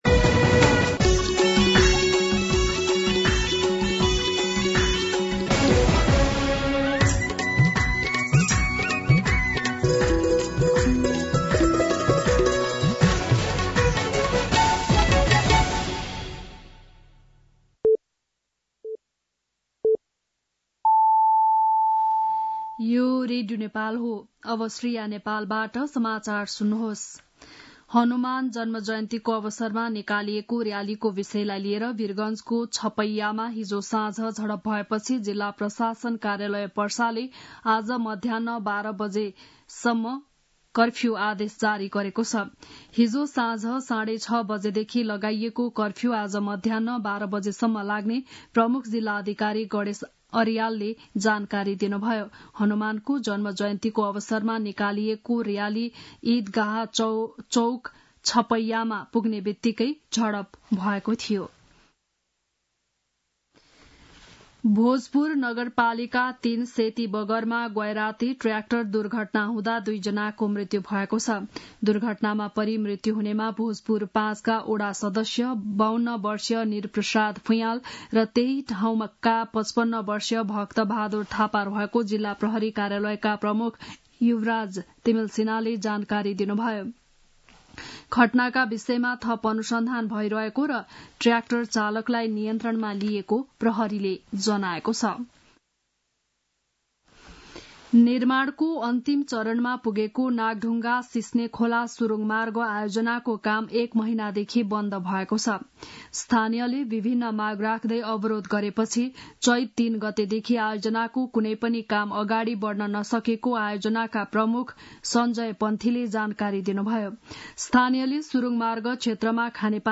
बिहान ११ बजेको नेपाली समाचार : ३० चैत , २०८१
11-am-Nepali-News-3.mp3